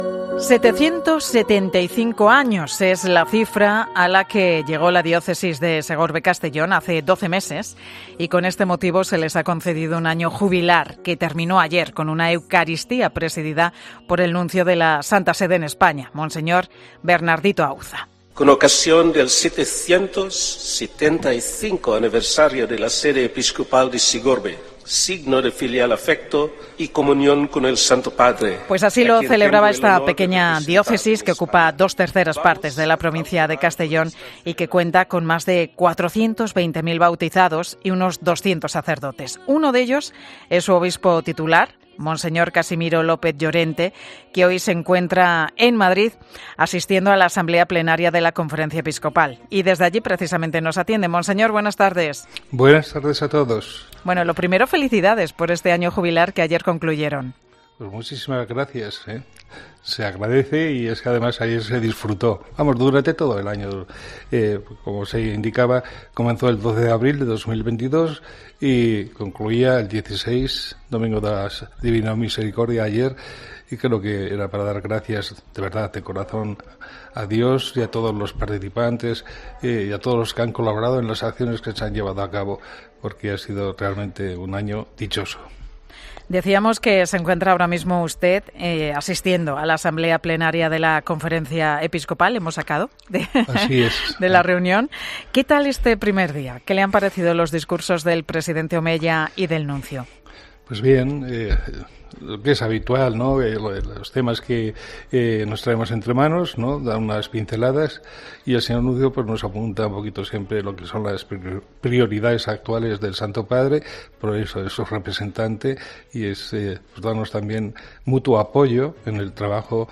El obispo de Segorbe-Castellón hace balance en 'Mediodía COPE' del Año Jubilar que terminó ayer